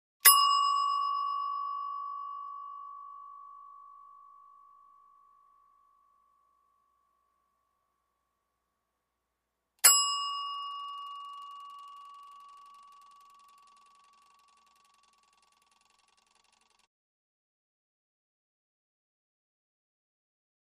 Egg Timer Bell Dings With Long Ring-off, Close Perspective. Some With Timer Ticking.